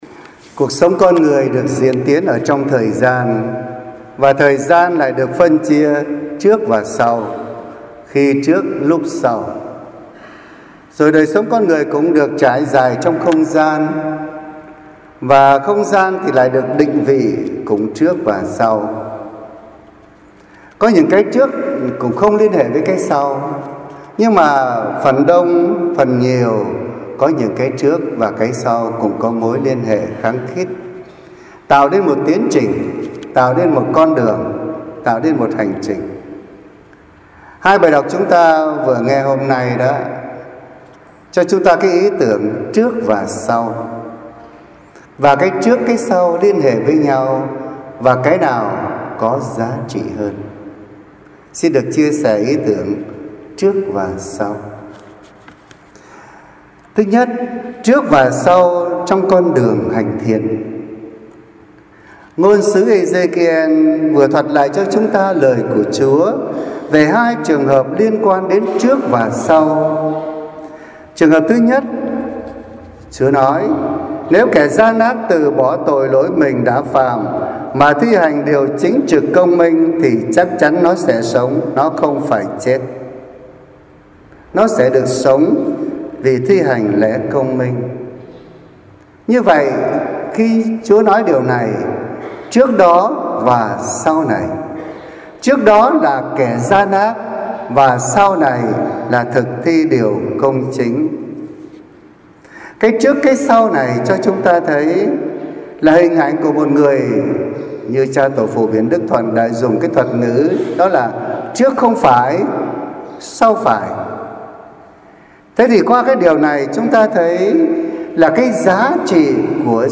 Suy niệm hằng ngày